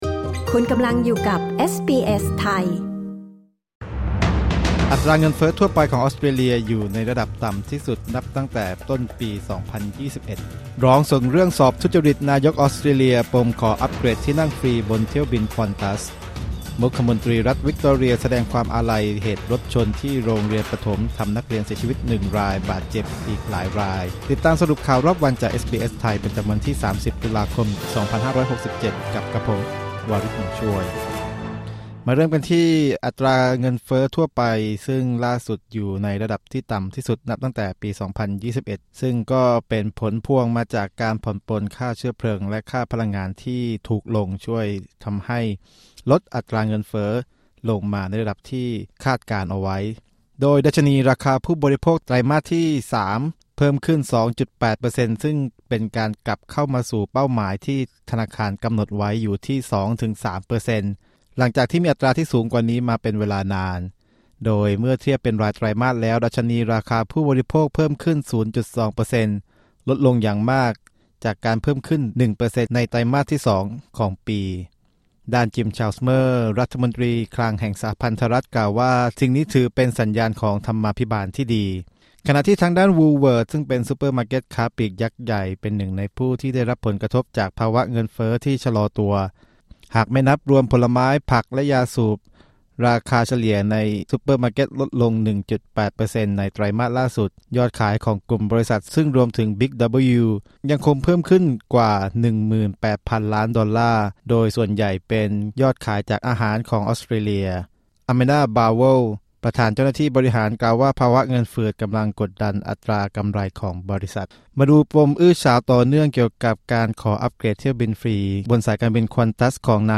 สรุปข่าวรอบวัน 30 ตุลาคม 2567